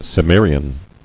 [Cim·me·ri·an]